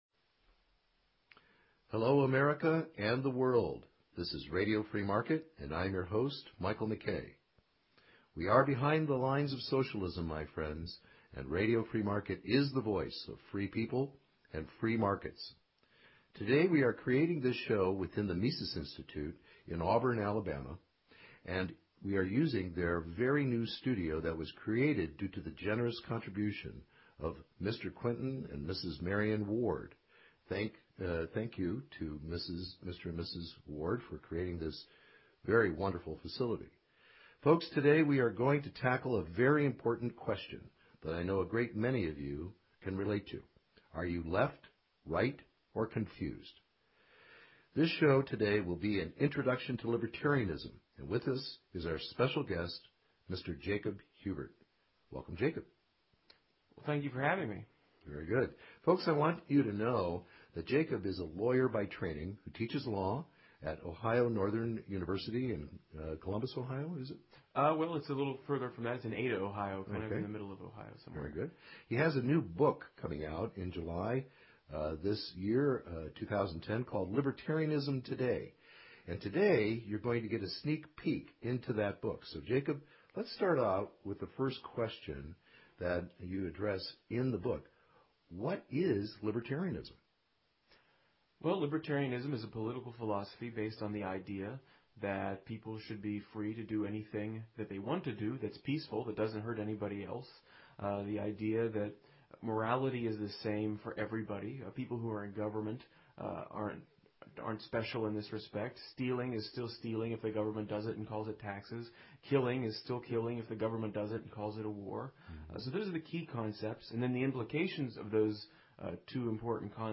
You will learn the very unique perspective that libertarians have on Healthcare, Education, Property, Justice and Peace. This interview is one that you will want to share with your family and friends.